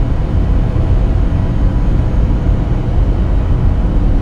digger.ogg